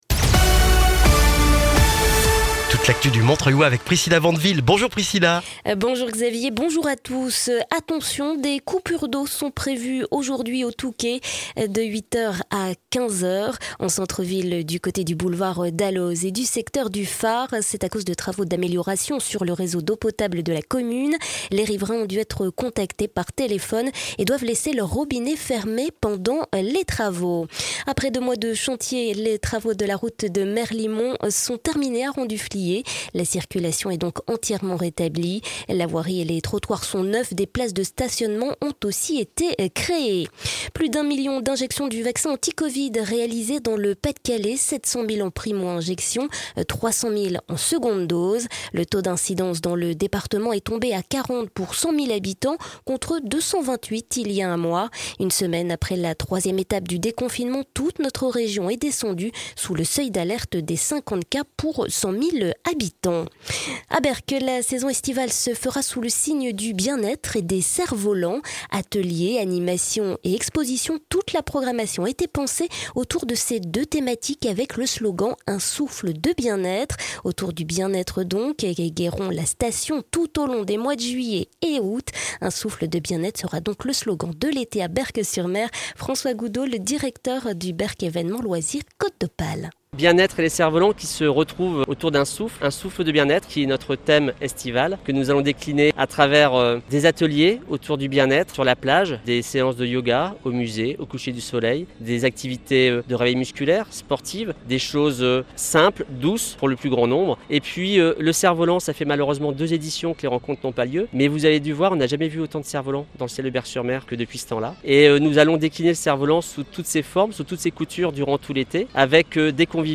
Le journal du mercredi 16 juin dans le Montreuillois